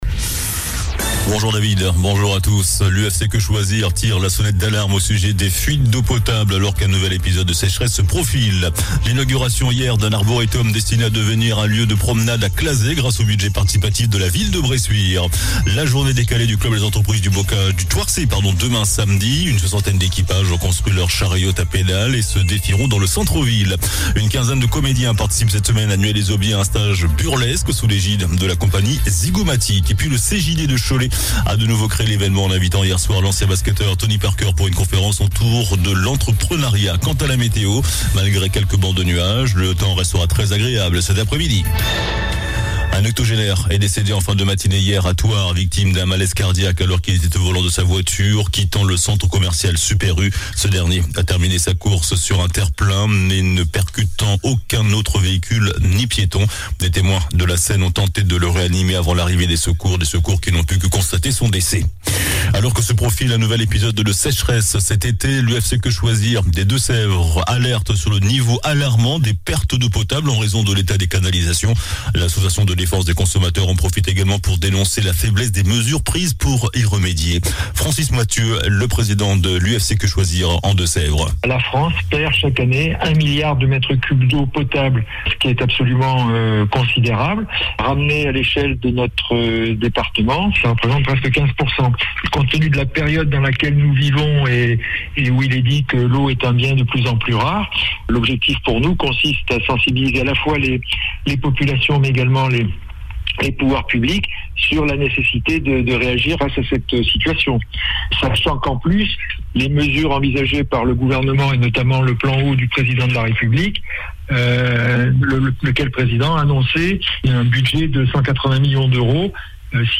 JOURNAL DU VENDREDI 30 JUIN ( MIDI )